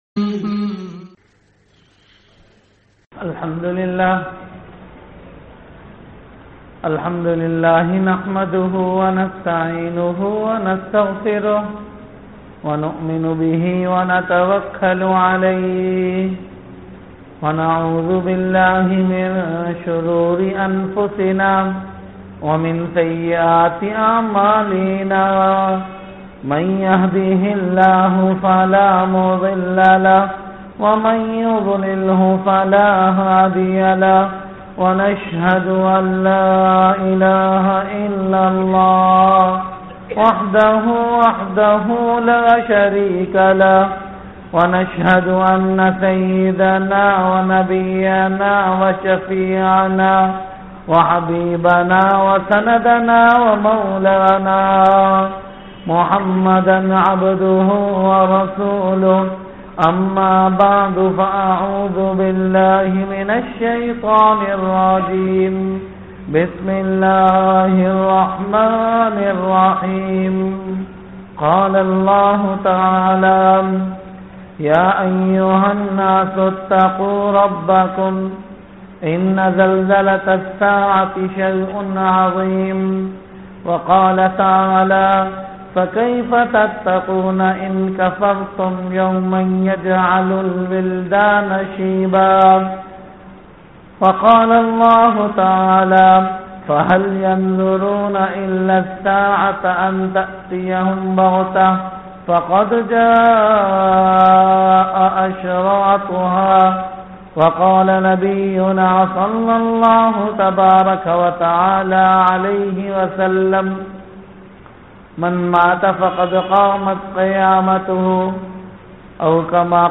Bayanat - Social Cares